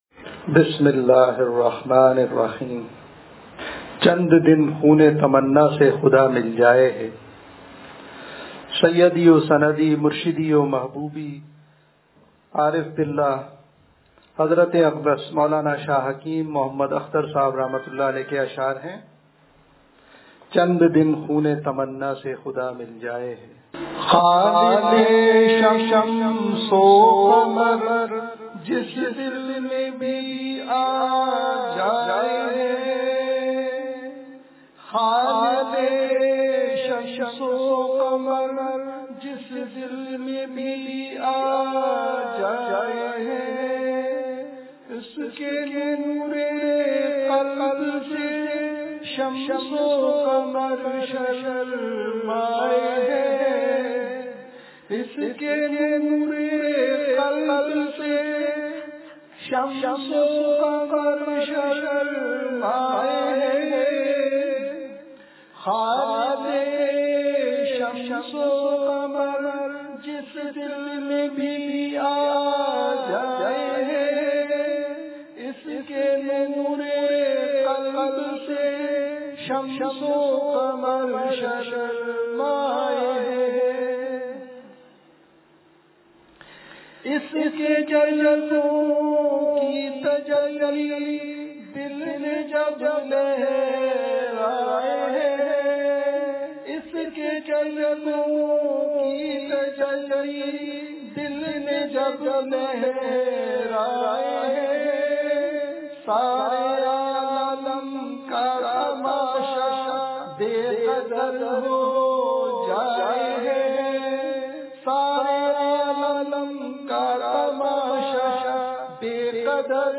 چند دن خونِ تمنا سے خدا مل جائے ہے – دنیا کی حقیقت – نشر الطیب فی ذکر النبی الحبیب صلی اللہ علیہ وسلم – اتوار مجلس